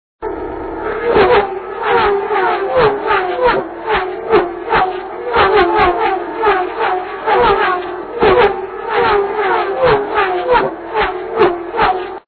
Tonos FX Carburando
Peloton en recta del TC